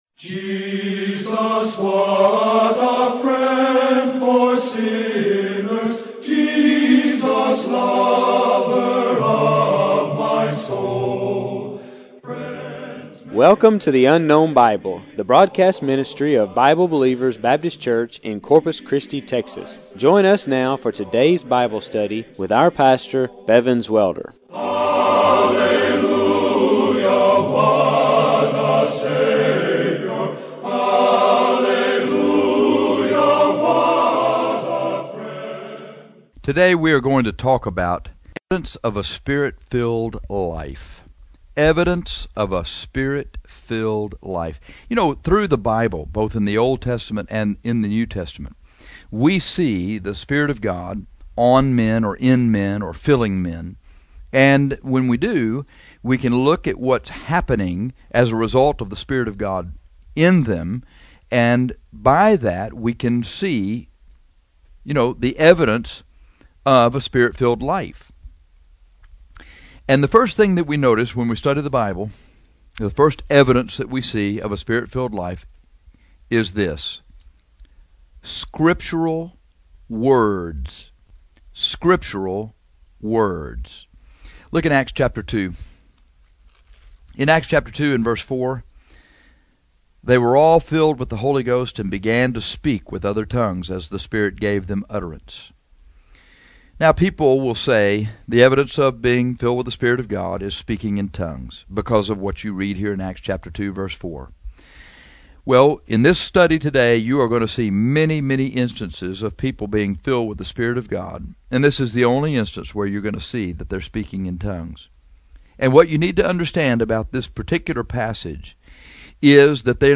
This lesson deals with the evidence of a Spirit filled life.